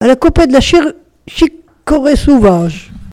Localisation Sables-d'Olonne (Les)
locutions vernaculaires
Catégorie Locution